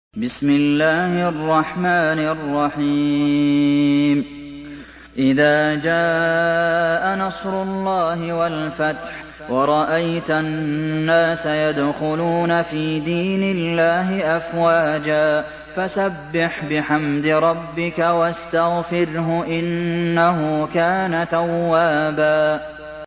Surah النصر MP3 in the Voice of عبد المحسن القاسم in حفص Narration
مرتل حفص عن عاصم